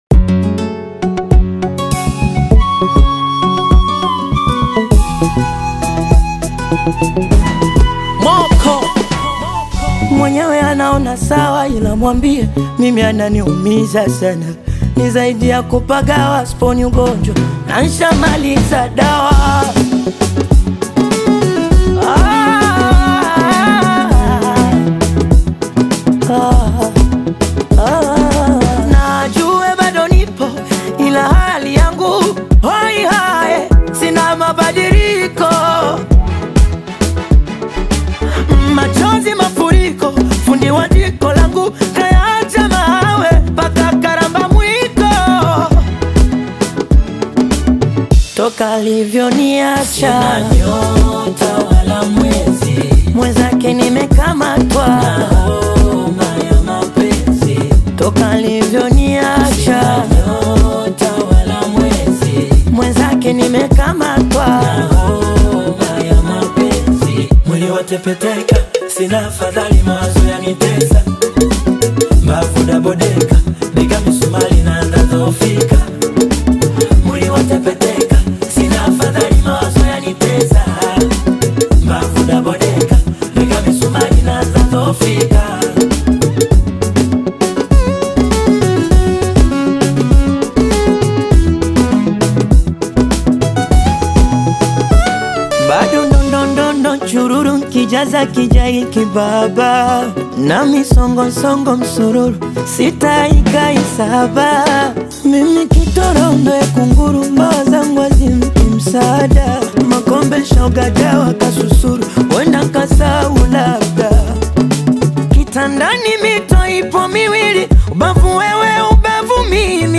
is a melodious tune